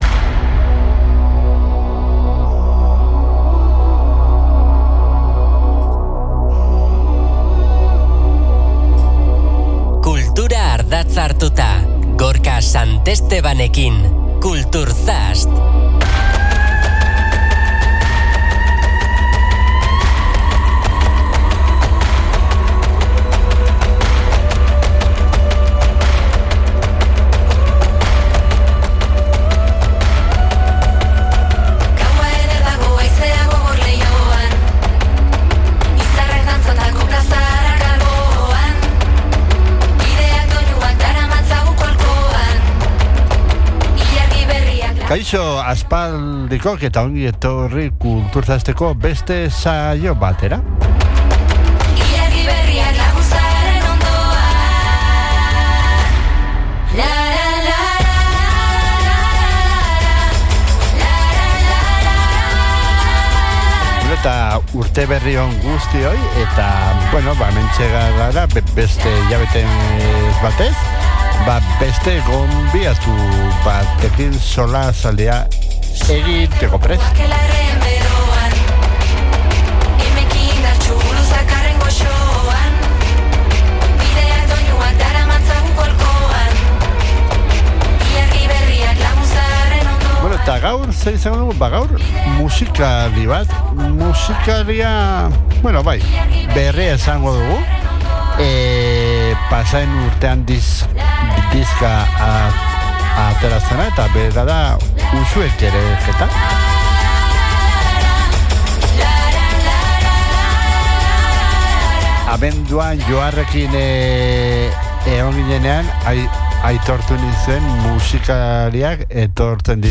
Agendako hitzordu garrantzitsuak, kultur-egileei elkarrizketak eta askoz ere gehiago bilduko ditu ordubeteko tartean.